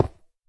stone1.ogg